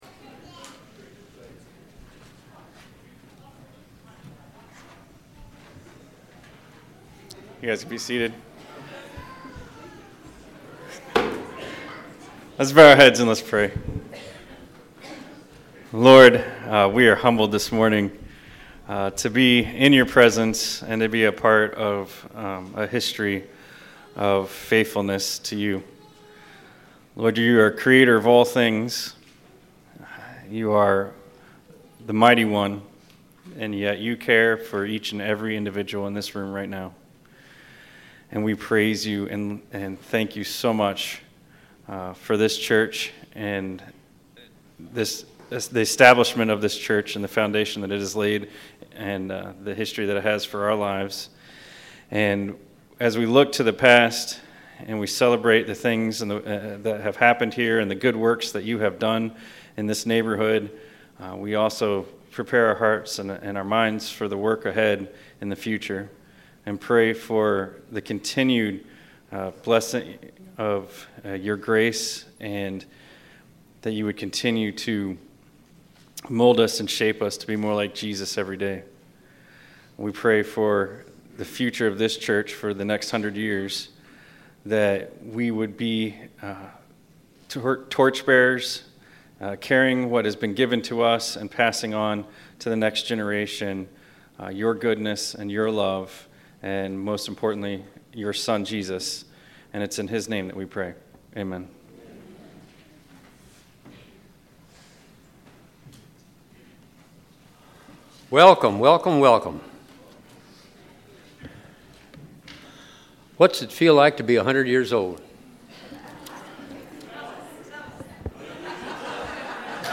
GCC 100th YEAR CELEBRATION SERVICE – GARFIELD CHRISTIAN CHURCH
GCC CENTENNIAL CELEBRATION SERVICE 6/26/2022